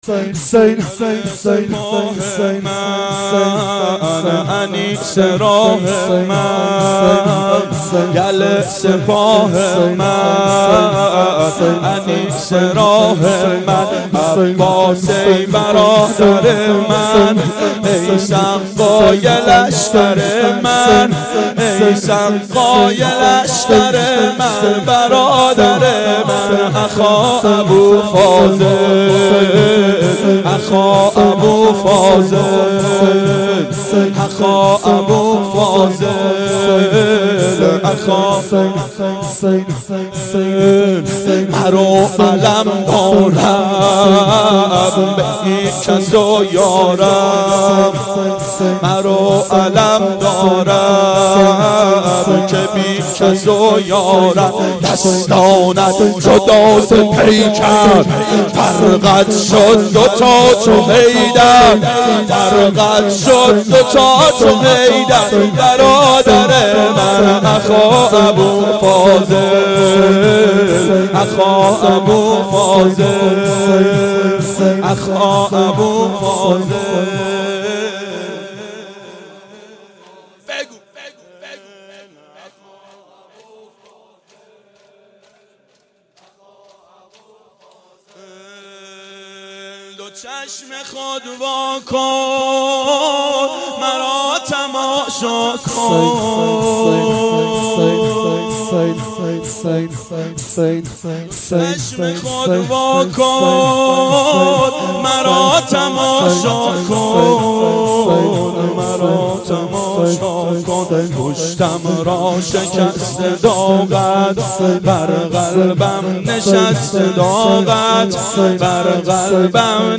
شب تاسوعا 92 هیأت عاشقان اباالفضل علیه السلام منارجنبان